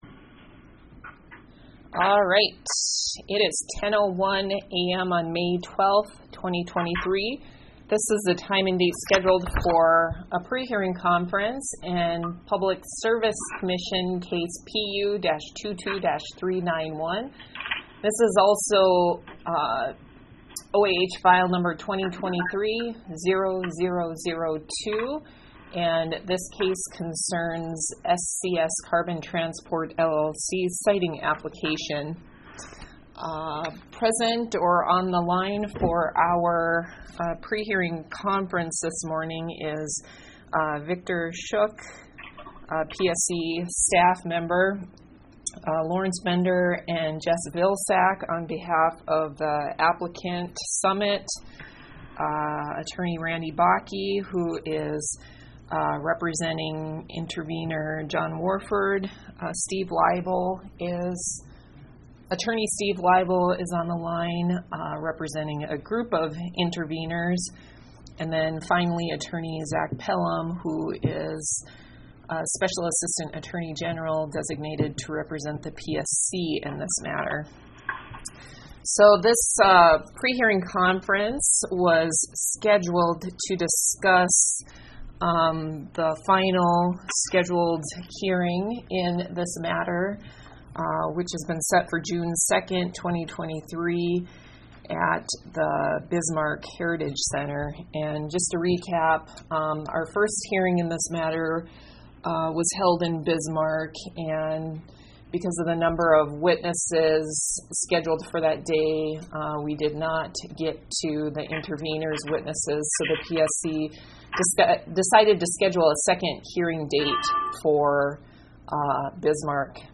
Electronic recording of 12 May 2023 prehearing conference